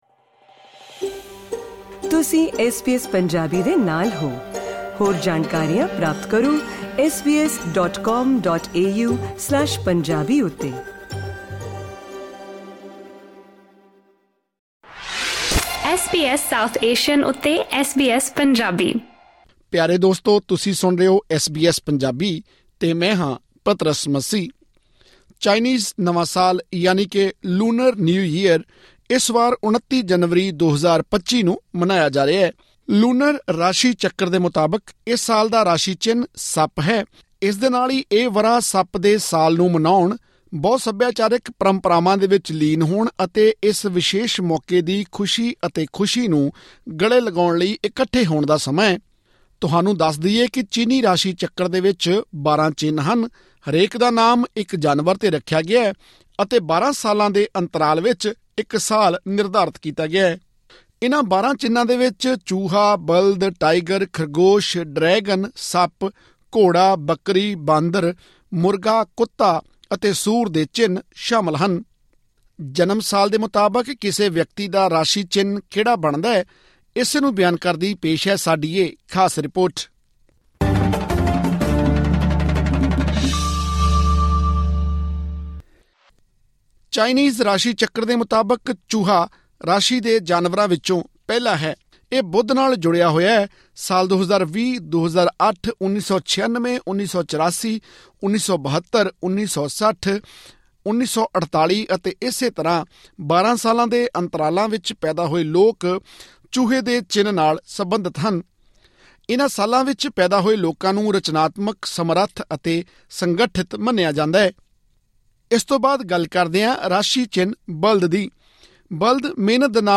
ਜਨਮ ਸਾਲ ਦੇ ਮੁਤਾਬਿਕ ਤੁਹਾਡਾ ਚਿੰਨ੍ਹ ਕਿਹੜਾ ਹੈ, ਇਹ ਜਾਨਣ ਲਈ ਸੁਣੋ ਸਾਡੀ ਖਾਸ ਰਿਪੋਰਟ